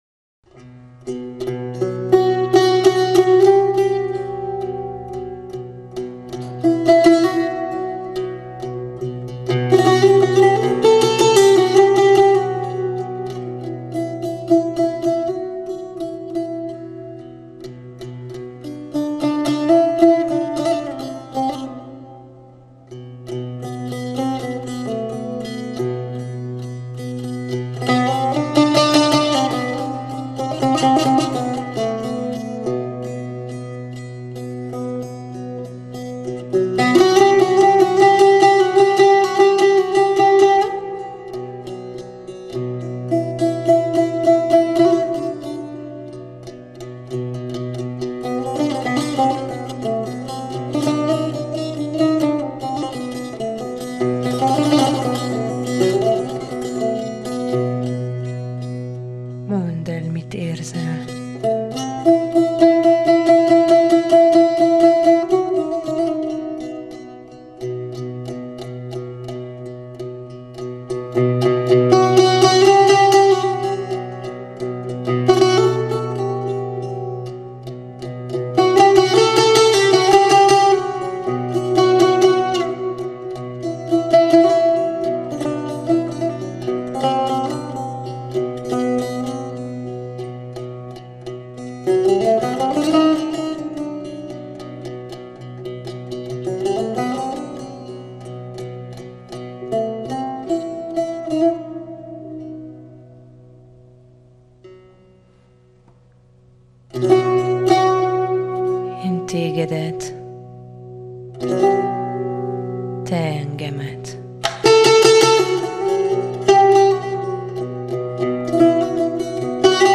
纪录片背景音乐